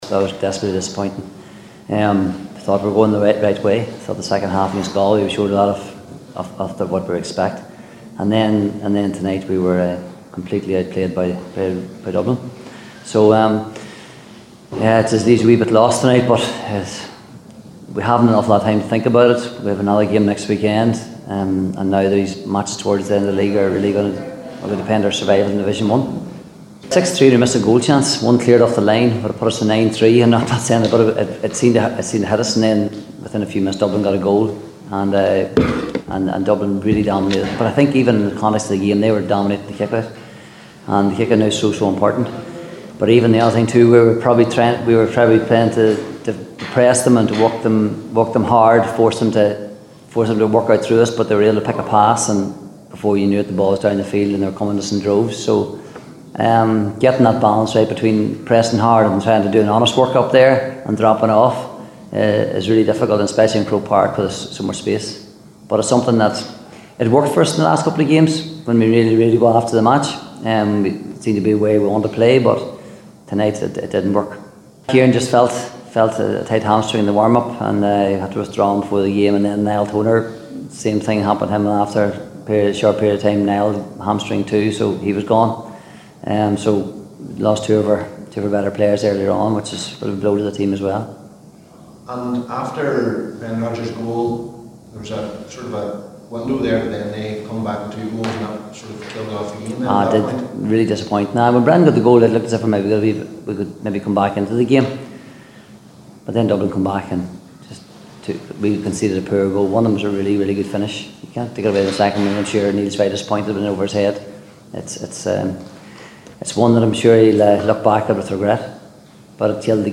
After the game, Derry manager Paddy Tally said the performance was “desperately disappointing”…